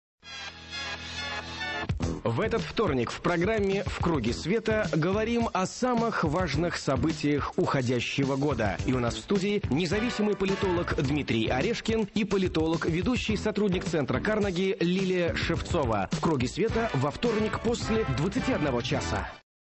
программа Светланы Сорокиной
соведущий - Юрий Кобаладзе
Аудио: анонс –